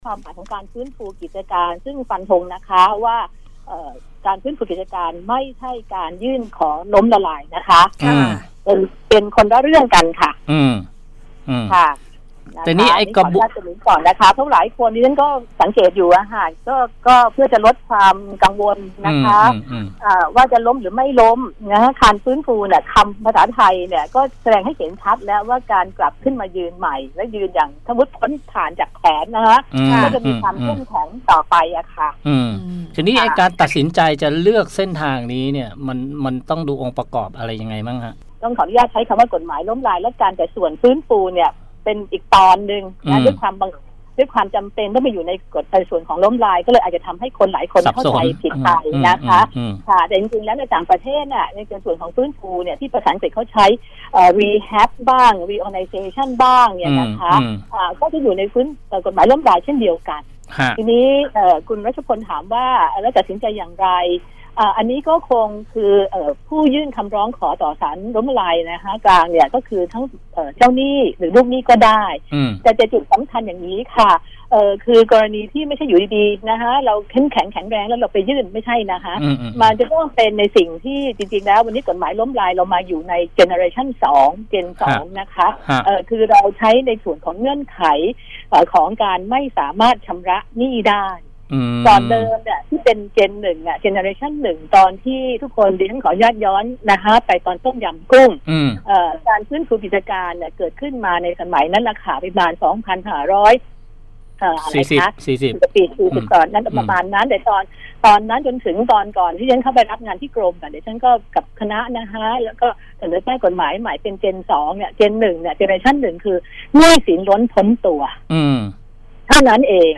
สัมภาษณ